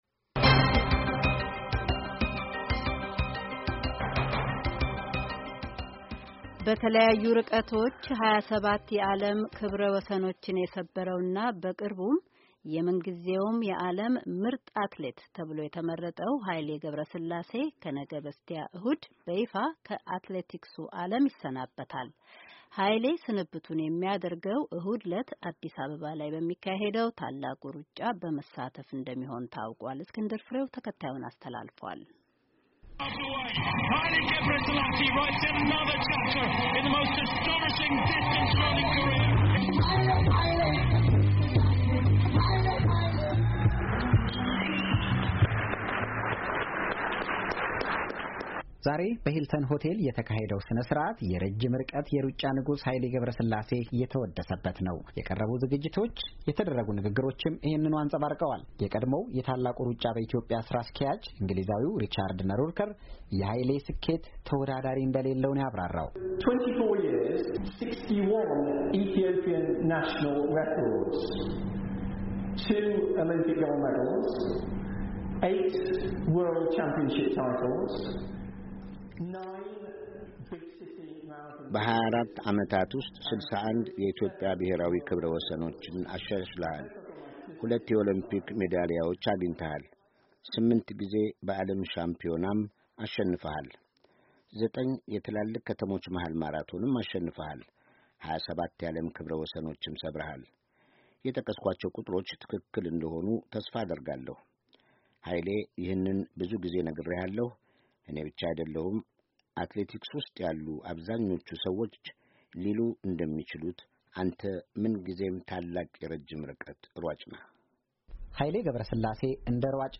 ሙሉ ዘገባ